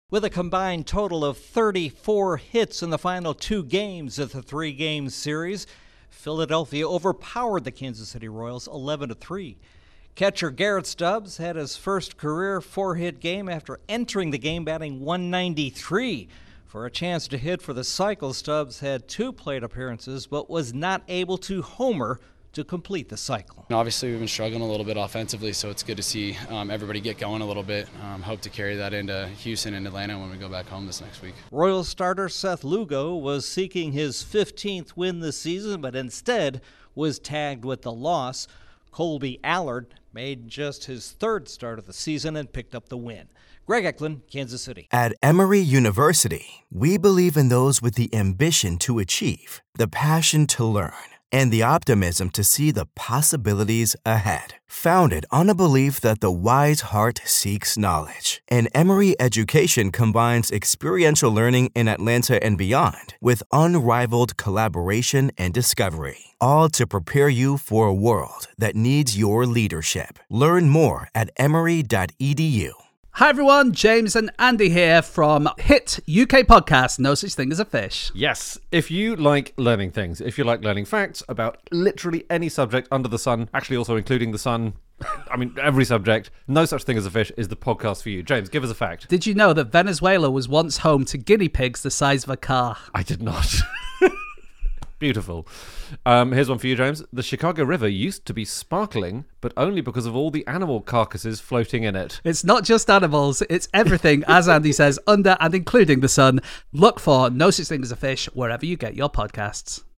The Phillies clobber the Royals for the second straight day. Correspondent